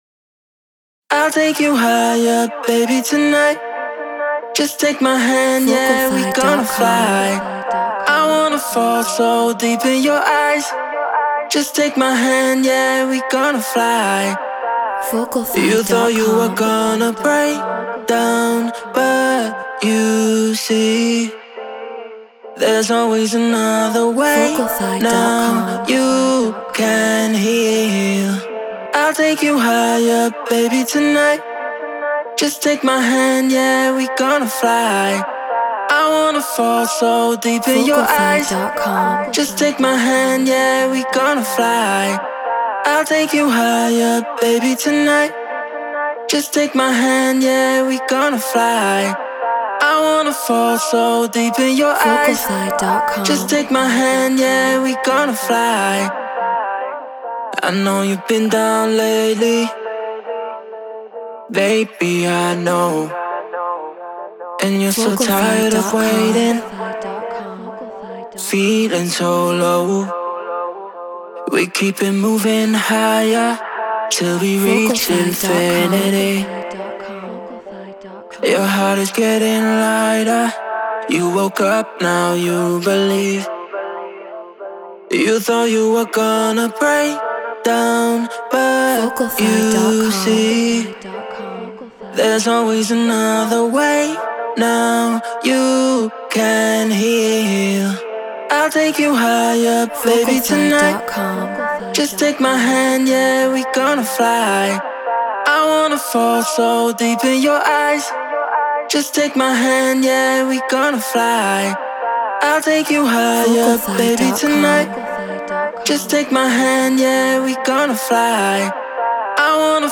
UKG 138 BPM C#min
Treated Room